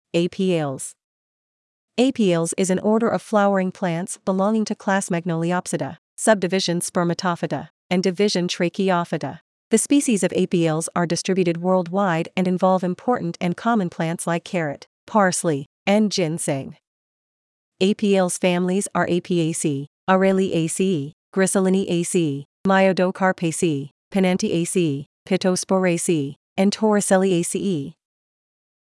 Apiales Pronunciation
Apiales-Pronunciation.mp3